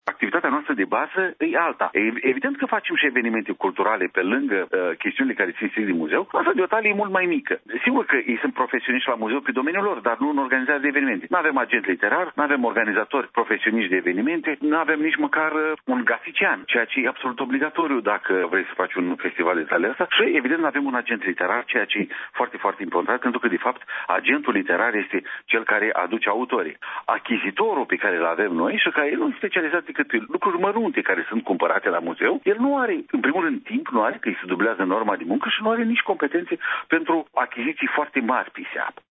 Într-o declaraţie acordată postului nostru de radio, scriitorul a prezentat principalele avantaje ale gestionării acestui festival cu ajutorul unei fundaţii şi nu prin intermediul Muzeului Literaturii Române din Iaşi: